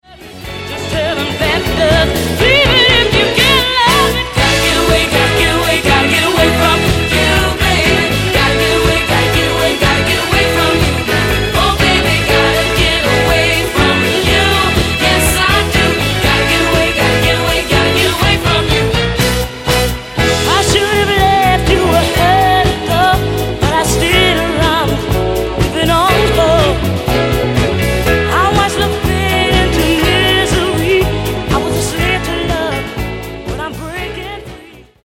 Genere:   Rare Disco Soul